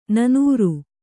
♪ nanūru